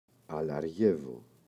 αλαργεύω [alaꞋrʝevo]